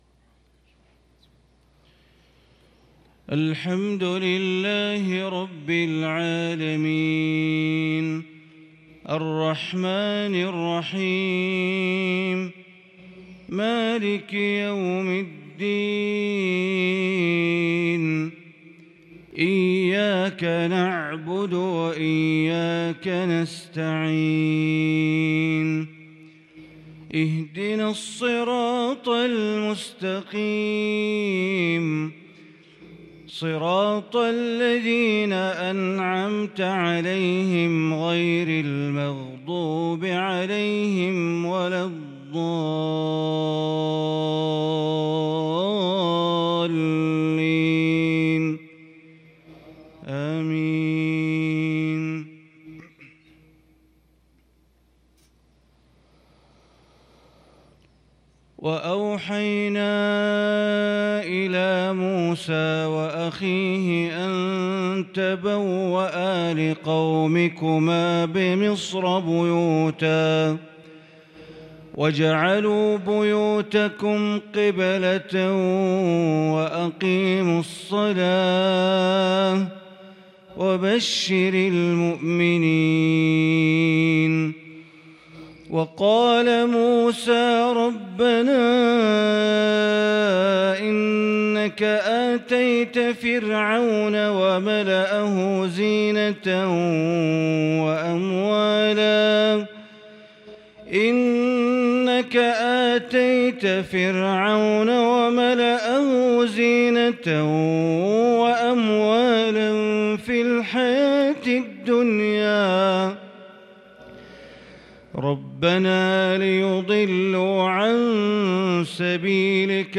صلاة الفجر للقارئ بندر بليلة 15 محرم 1443 هـ